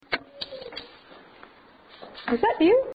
These are in the upstairs main room.
This was at a quiet point. You can also hear some car noise in the background.